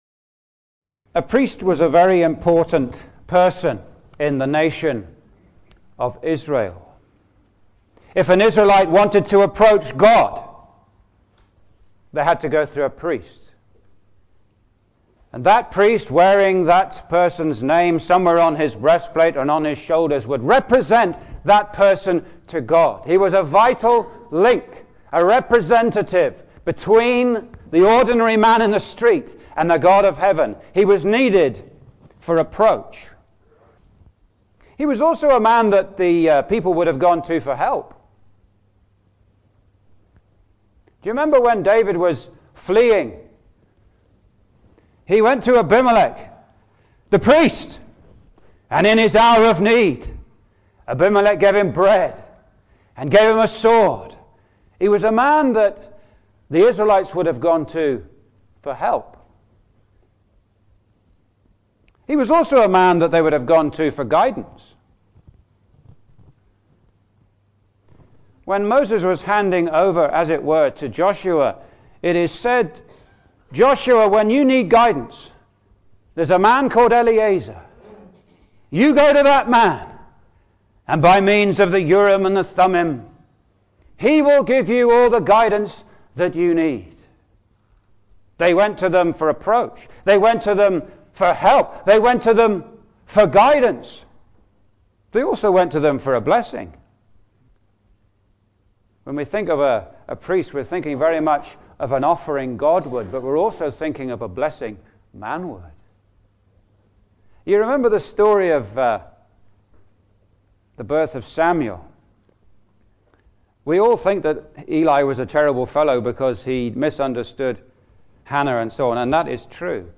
While the Lord Jesus Christ serves as our Great High Priest in heaven after the pattern of Aaron, He is not after the order of Aaron, but rather after the superior “order of Melchizedek”. (Recorded at the Newtownstewart Gospel Hall conference, Northern Ireland, New Year’s Day 2015)
Verse by Verse Exposition